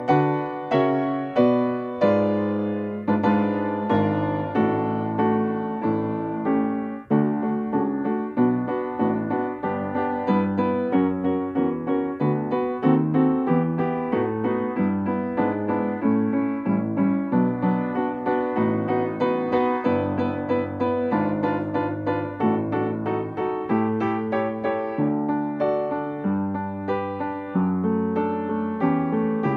akompaniamentu pianina
Nagrane z metronomem.
II wersja: 94 bmp – Nagranie uwzględnia zwolnienia.
Nagranie dokonane na pianinie Yamaha P2, strój 440Hz
piano